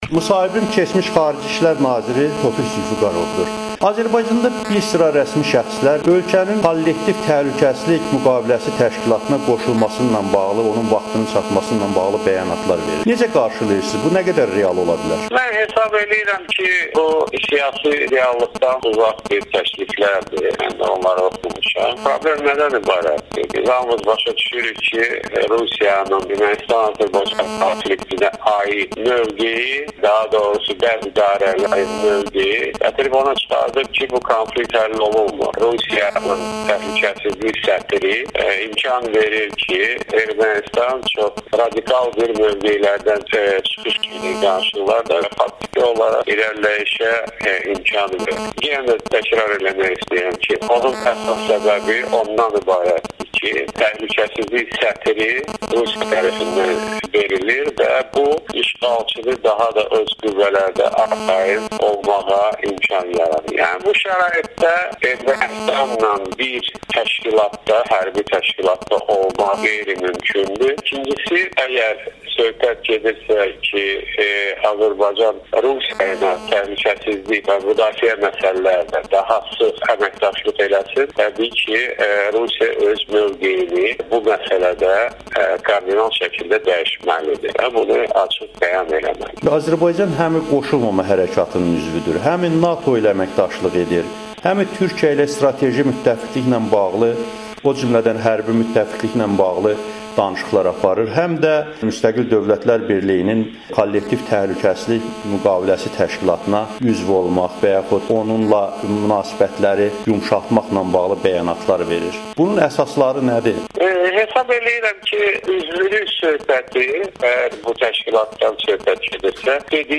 Keçmiş xarici işlər nazirinin Amerikanın Səsinə müsahibəsi